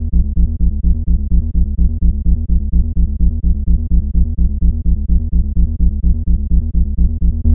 Index of /90_sSampleCDs/Club_Techno/Bass Loops
BASS_127_G#.wav